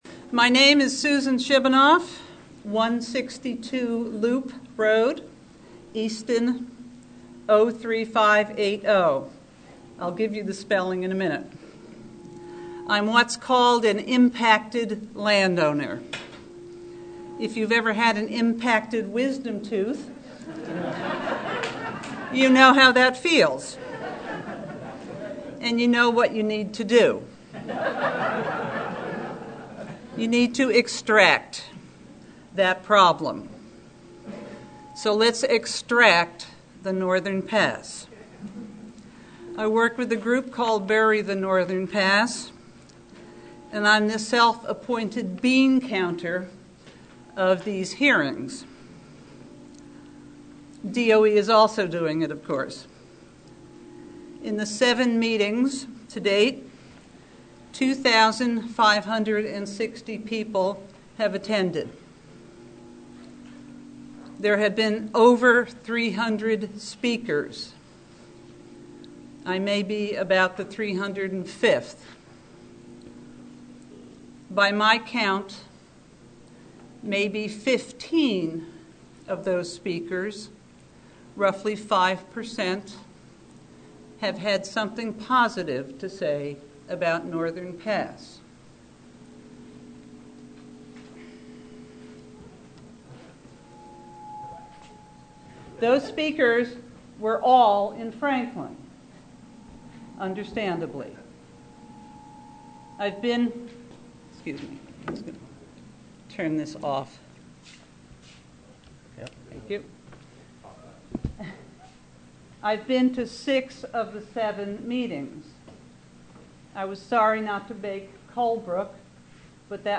Public Scoping Meeting- Haverhill 3/20/11: